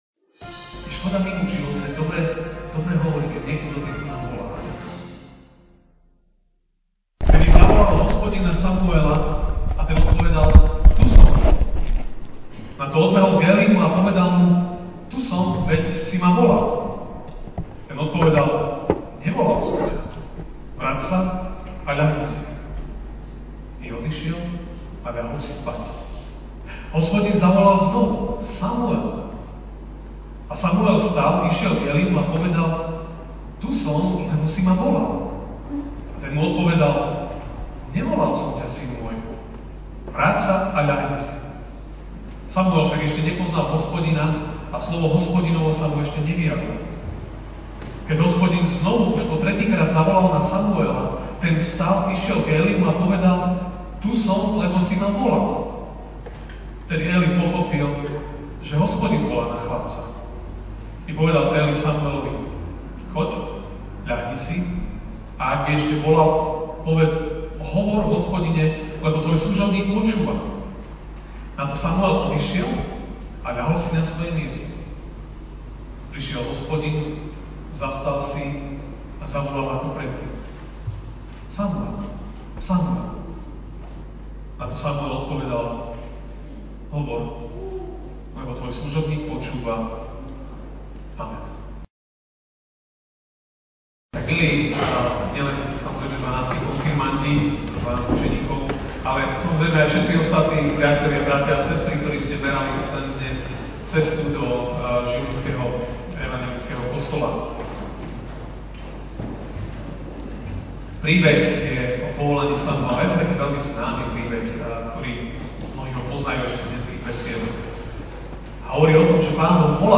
jún 03, 2018 Počúvaj, keď Boh hovorí MP3 SUBSCRIBE on iTunes(Podcast) Notes Sermons in this Series Ranná kázeň: Počúvaj, keď Boh hovorí (1S 3, 1-10) Chlapec Samuel slúžil Hospodinovi po boku Éliho.